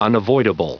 Prononciation du mot unavoidable en anglais (fichier audio)
Prononciation du mot : unavoidable